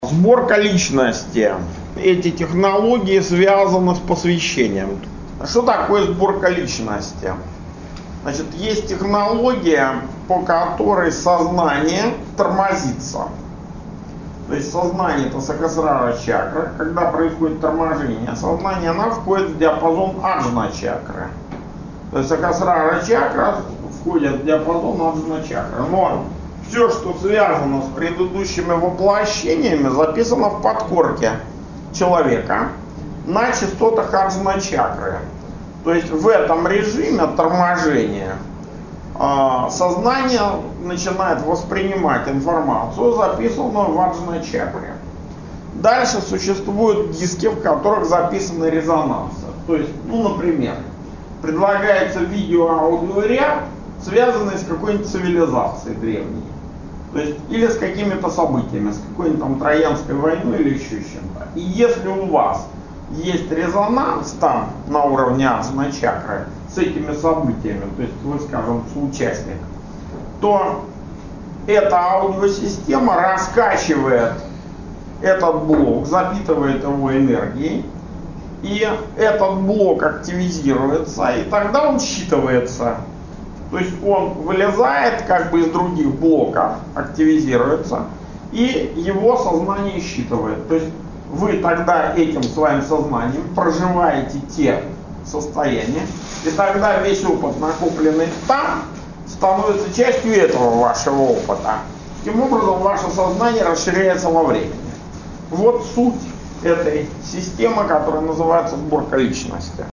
Аудионастройка Сборка личности.
Предлагаются аудиоматериалы, в которых записаны резонансы, связанные с какой-нибудь древней цивилизацией или с какими-то событиями, с Троянской войной или ещё что-то.